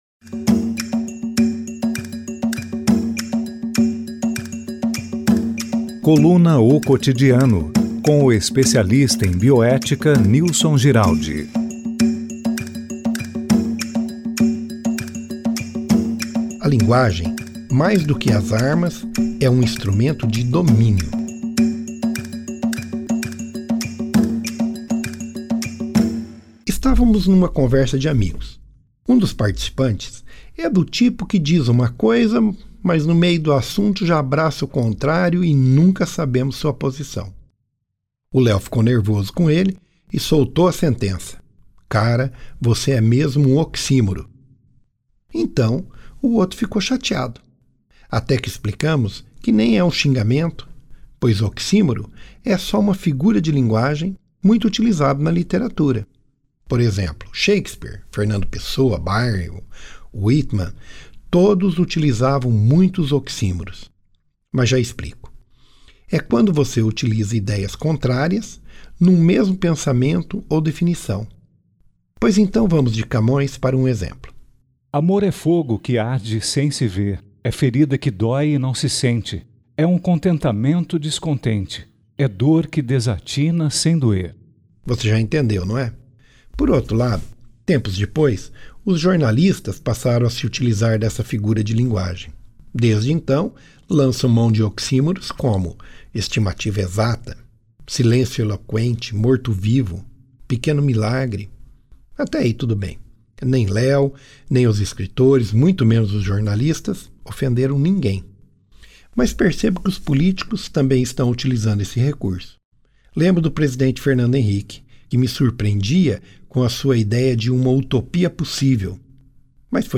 Áudio: Trabalhos técnicos e paisagem sonora